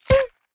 Minecraft Version Minecraft Version snapshot Latest Release | Latest Snapshot snapshot / assets / minecraft / sounds / enchant / thorns / hit4.ogg Compare With Compare With Latest Release | Latest Snapshot